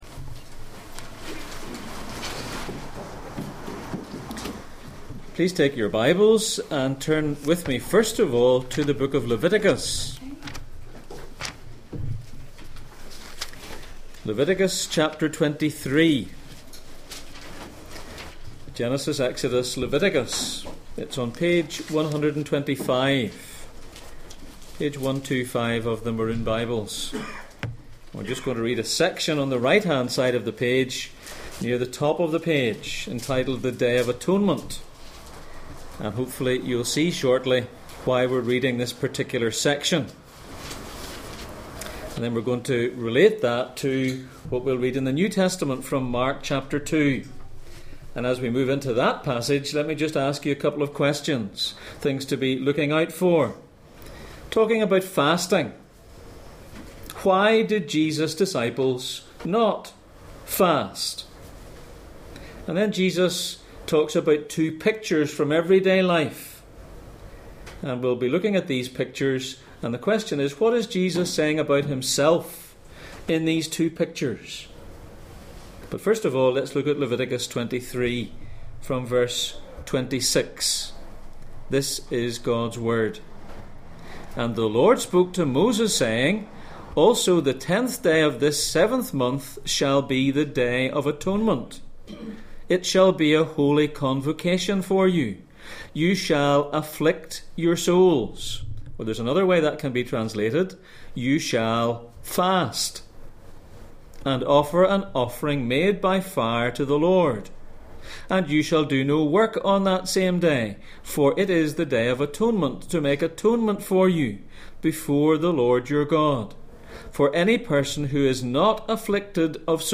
Series: Jesus in Mark Passage: Mark 2:18-22, Leviticus 23:26-32, Luke 18:12 Service Type: Sunday Morning